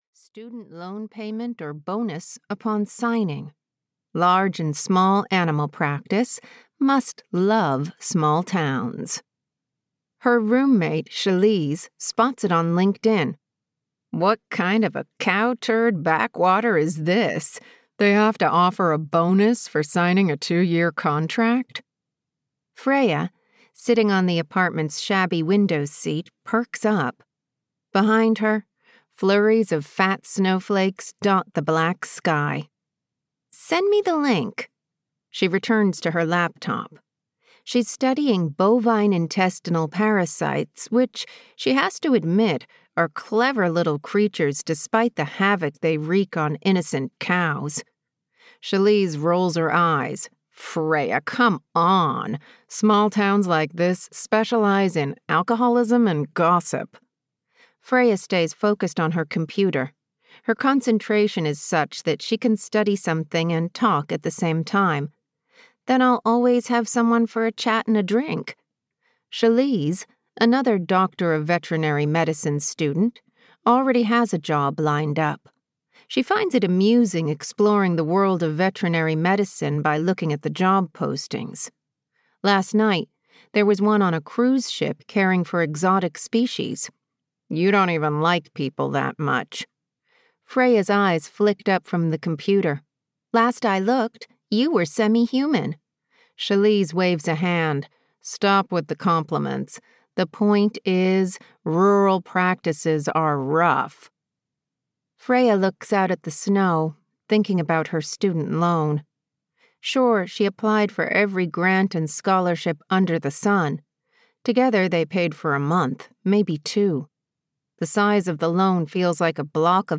The Gable House (EN) audiokniha
Ukázka z knihy